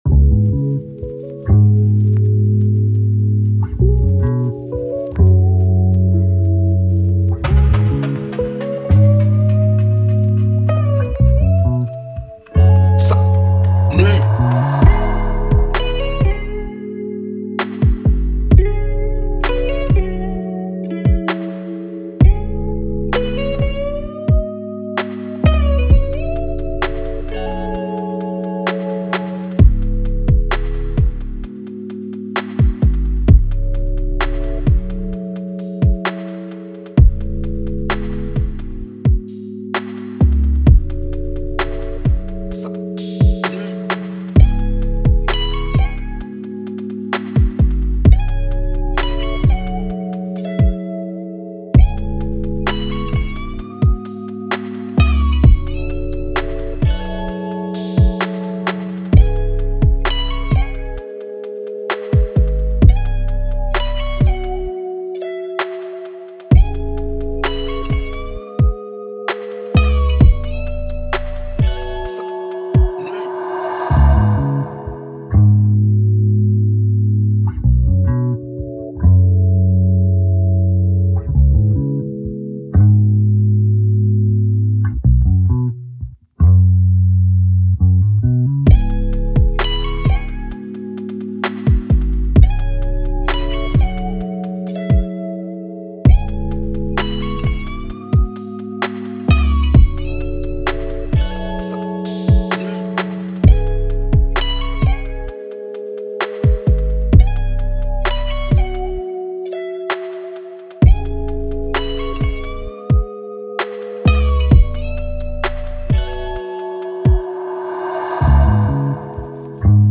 Positive, Chill, Vibe
Eletric Guitar, Lead, Drum, Bass